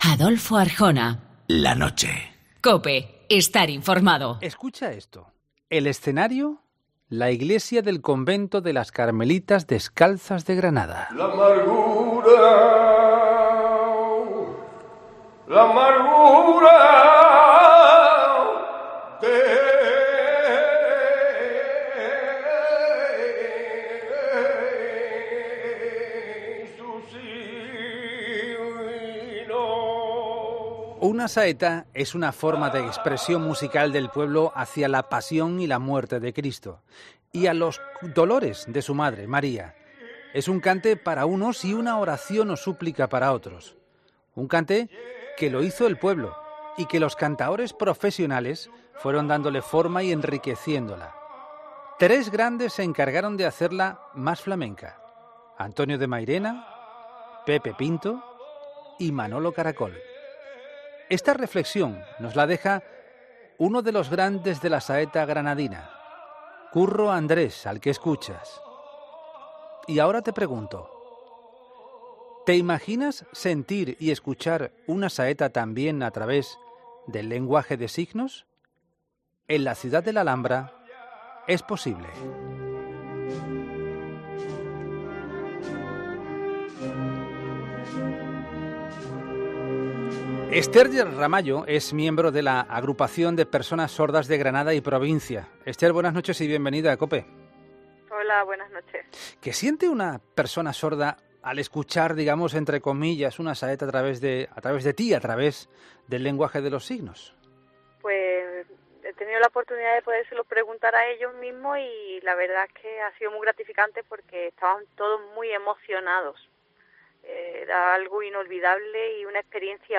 Escucha la entrevista a los protagonistas de la saeta inclusiva en La Noche de COPE, con Adolfo Arjona